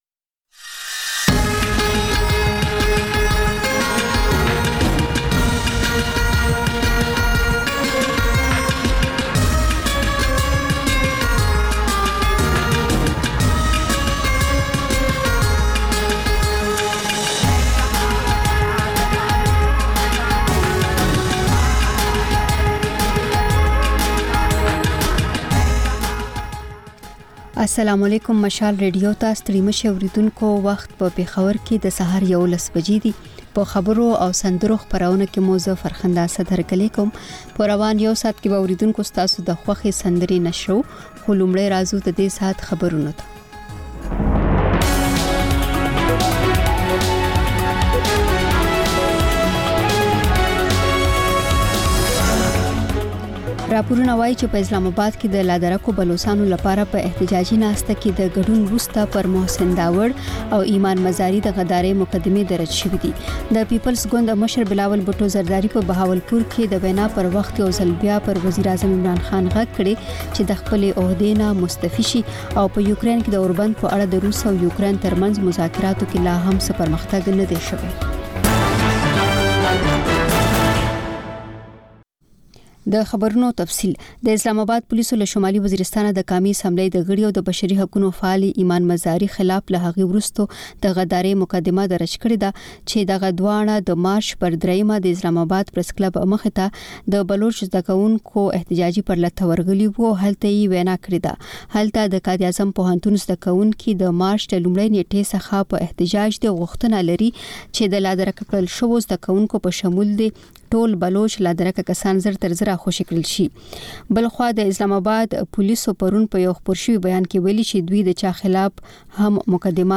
په دې خپرونه کې تر خبرونو وروسته له اورېدونکیو سره په ژوندۍ بڼه خبرې کېږي، د هغوی پیغامونه خپرېږي او د هغوی د سندرو فرمایشونه پوره کول کېږي.